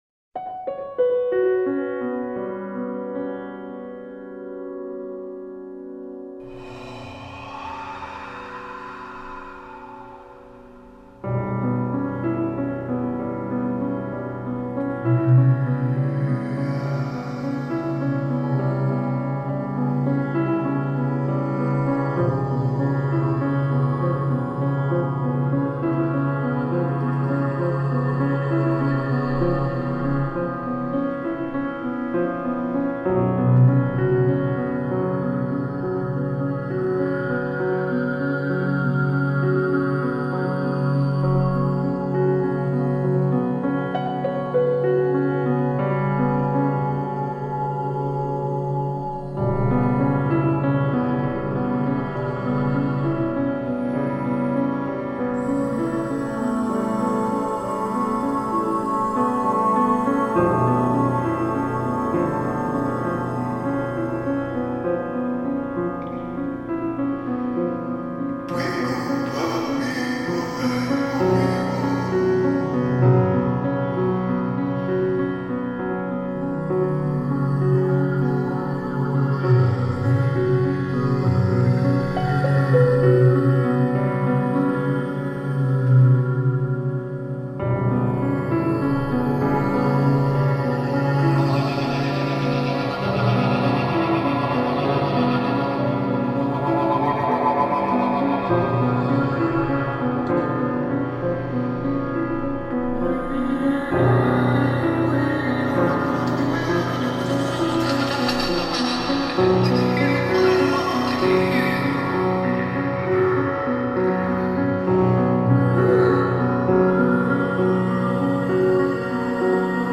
Tontrommel (Claydrum)